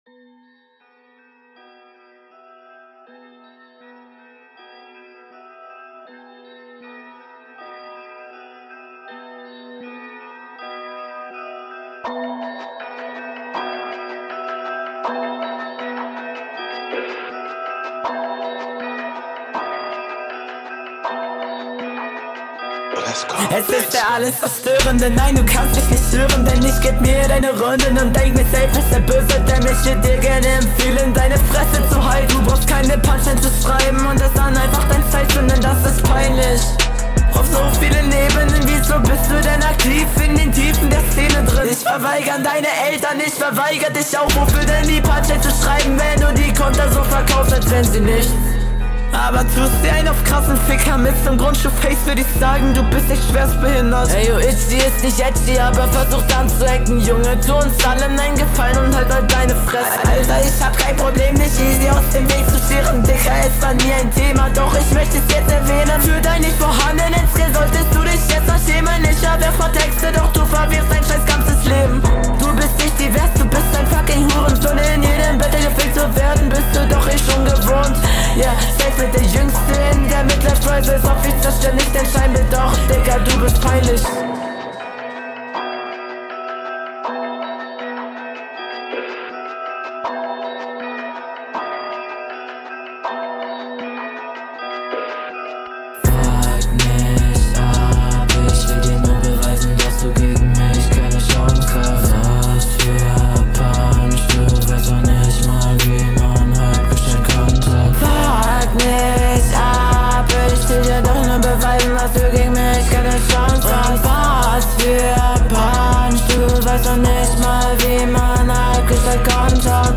Flow: Sehr nicer Flow. Du hast auf jeden fall Routine und nice Patterns!
Flow ist im allgemeinen ganz gut, aber nichts krasses.